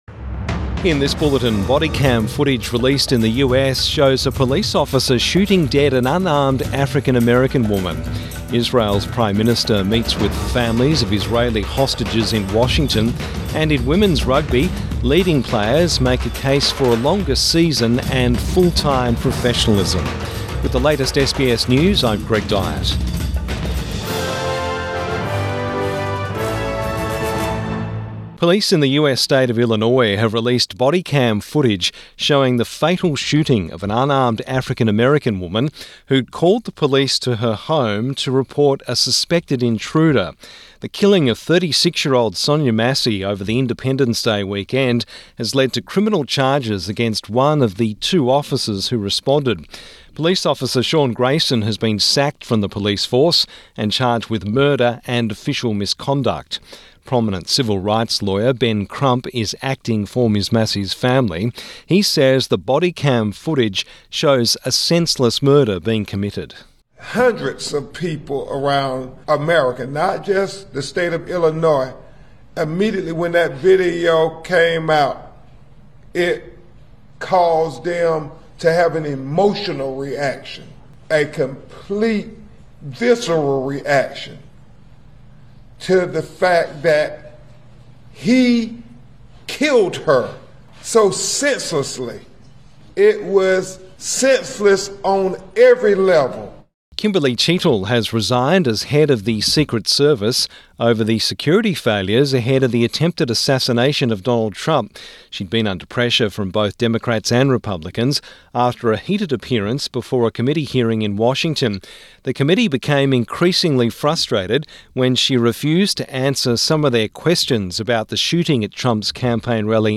Morning News Bulletin 24 July 2024